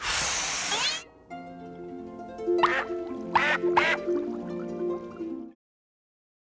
bubbleblow.wav